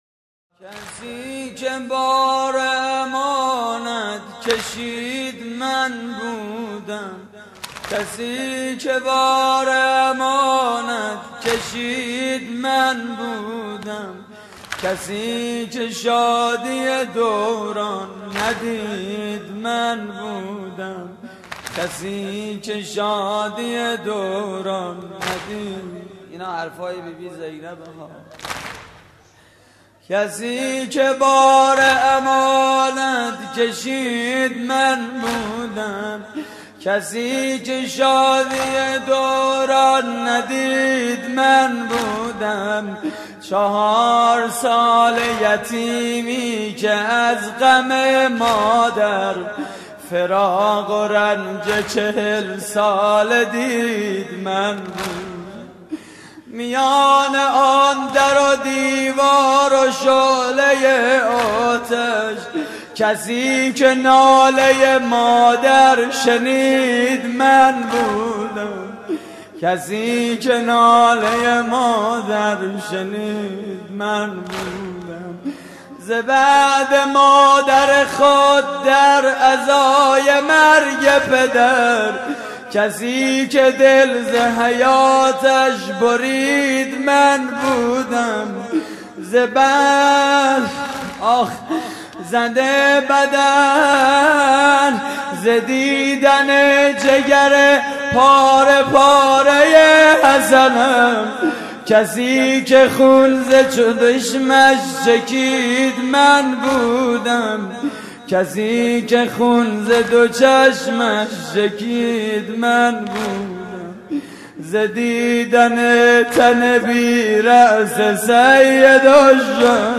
مراسم عزاداری شام غریبان حسینی